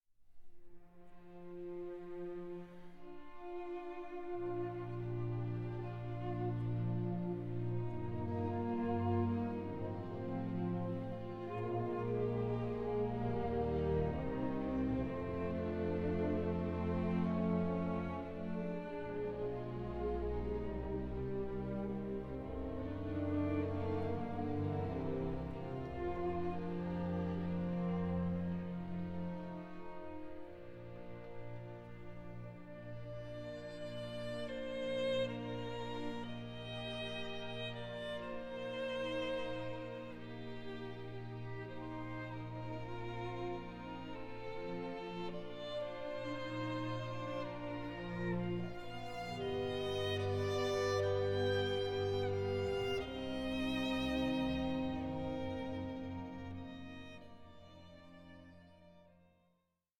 Langsam 6:21